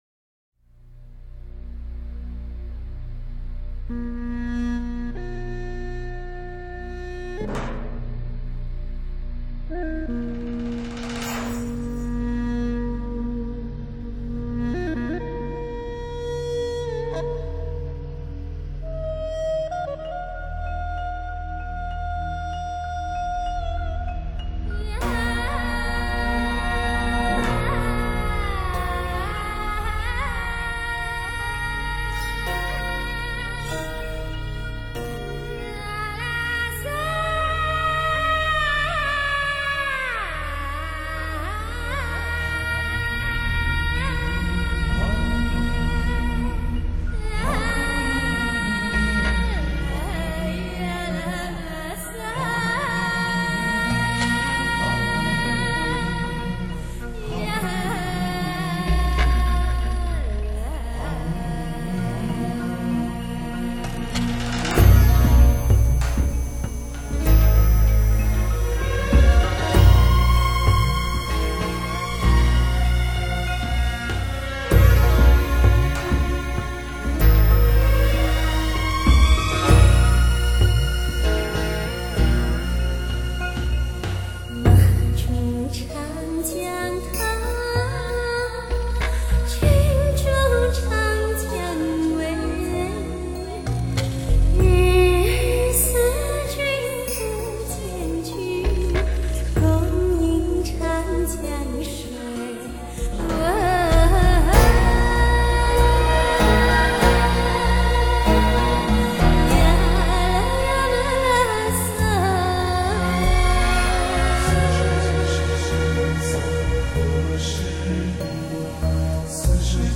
以二胡、古筝、笛子、琵琶等民族乐器为主，辅以电子合成器、MIDI和管弦乐团，融古风与现代于一炉
她的演唱具有一种高洁的气质，清丽出众，飘然若仙。
集东方古典高雅的气质与西方不羁脱俗的个性，其演唱风格飘逸清新，赋有先人之灵性和新世纪人类的感性。